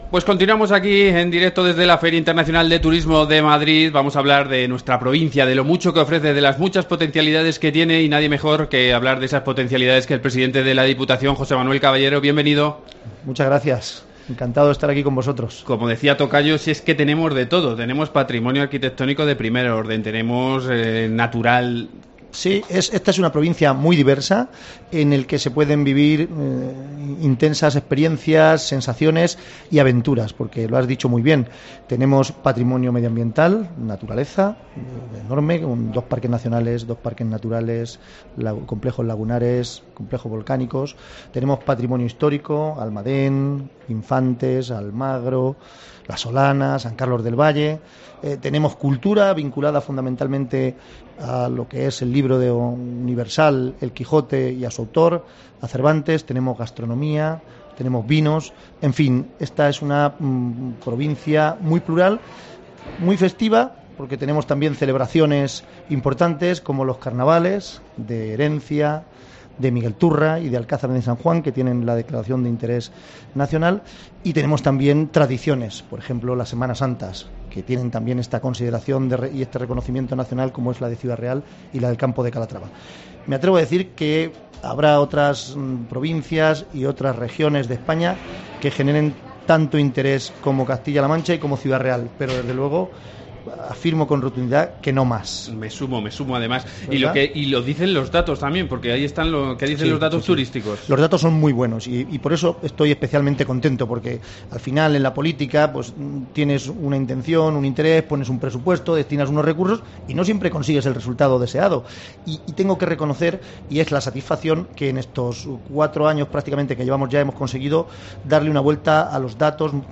José Manuel Caballero, presidente Diputación Ciudad Real, en FITUR